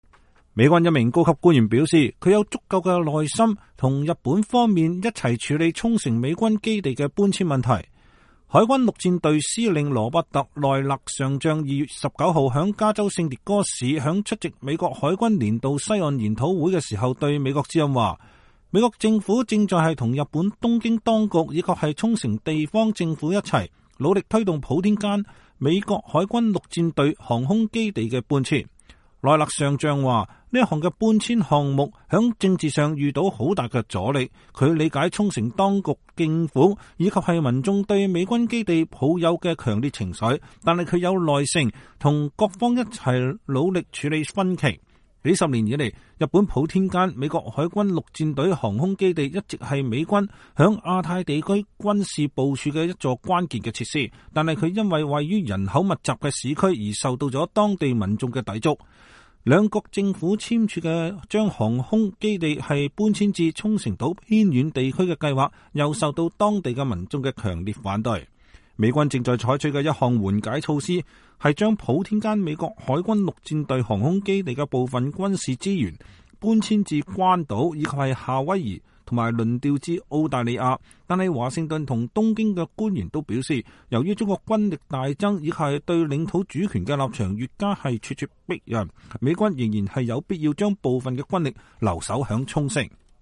海軍陸戰隊司令羅伯特·奈勒上將(Robert Neller) 2月19日在加州聖迭戈市出席美國海軍年度西岸研討會時對美國之音說，美國政府正在與日本東京當局和沖繩地方政府一道，努力推動普天間美國海軍陸戰隊航空基地的搬遷。